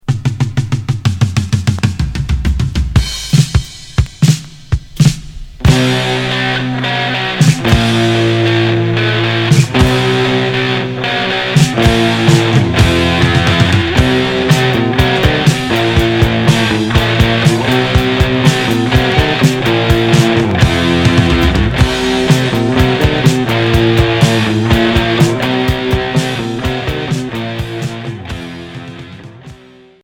Rock hard Unique 45t retour à l'accueil